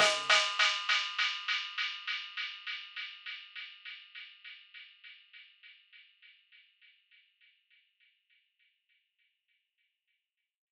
KIN Dub Delay.wav